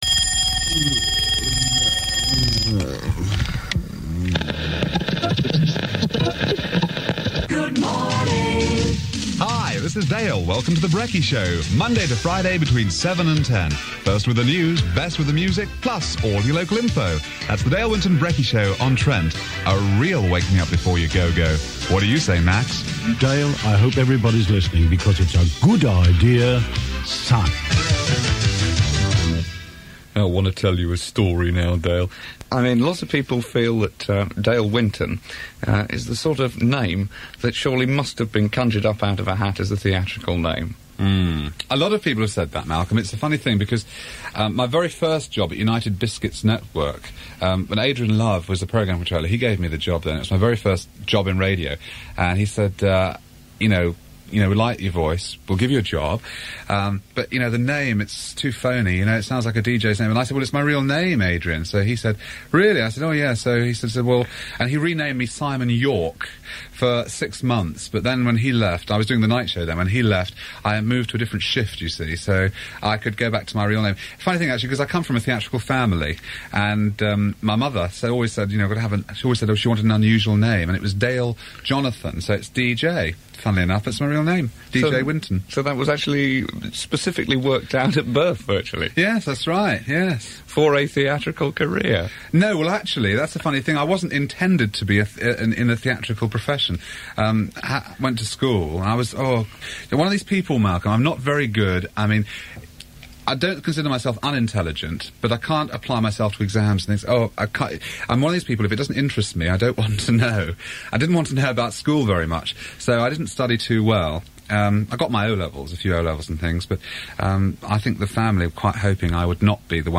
Dale Winton interview in early 80s